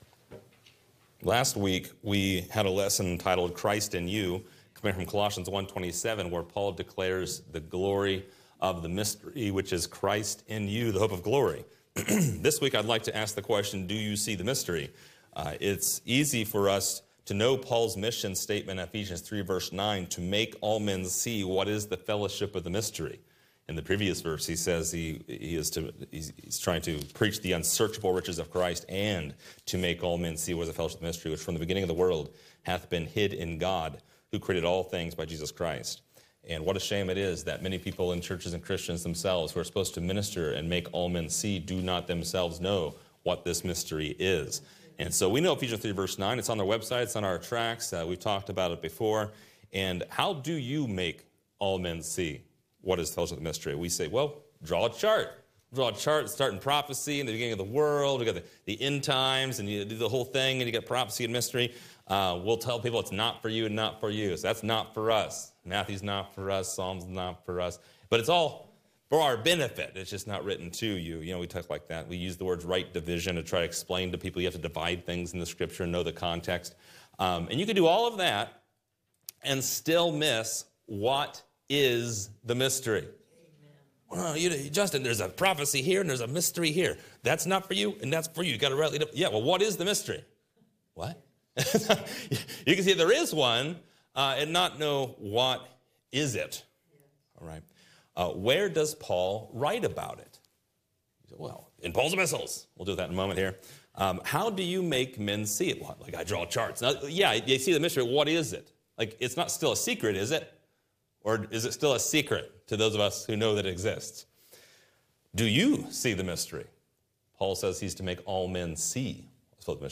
This lesson answers these questions and more from a mid-Acts dispensational perspective.